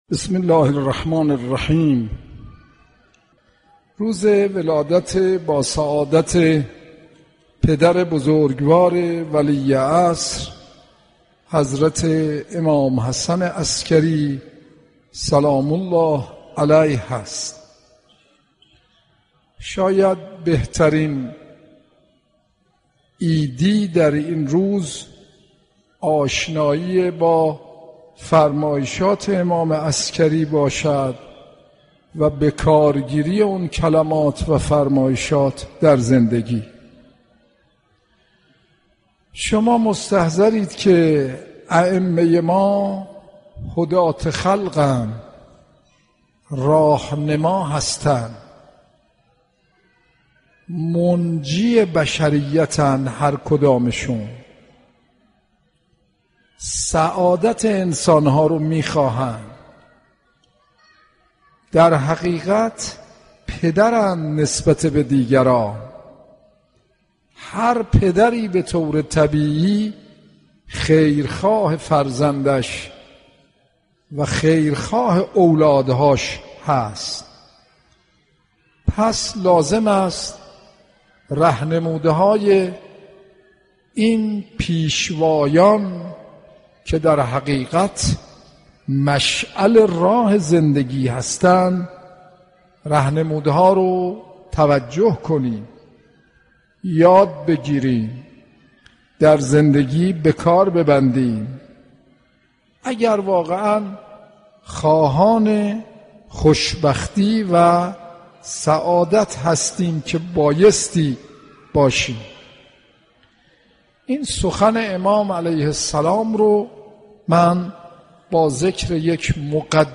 صوت سخنرانی مذهبی و اخلاقی